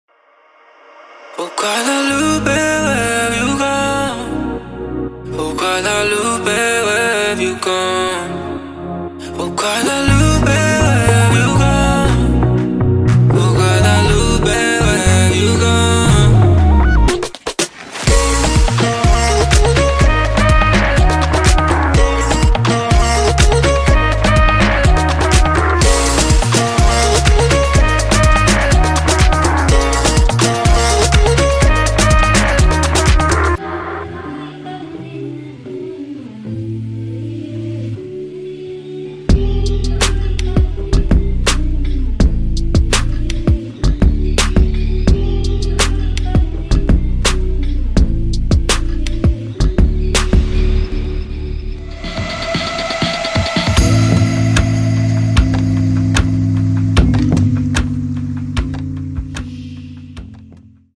Pop
utterly idyllic instrumental sounds